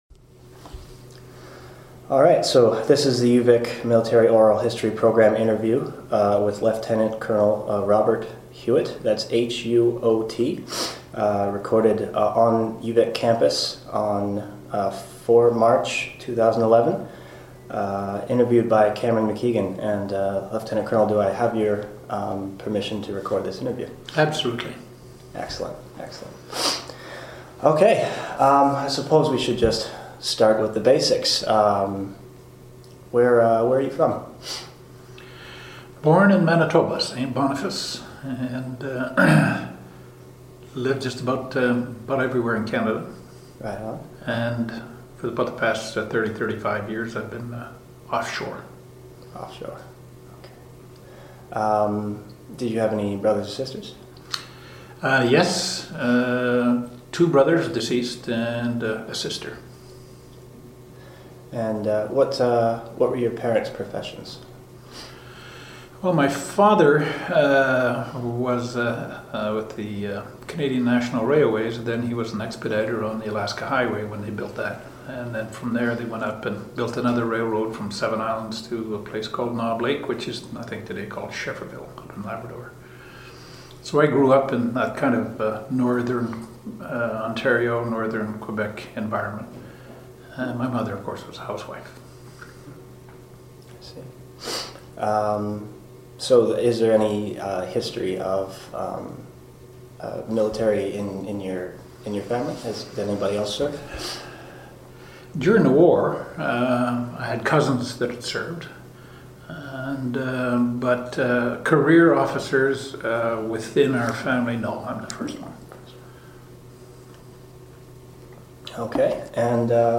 Interview took place on March 4, 2011.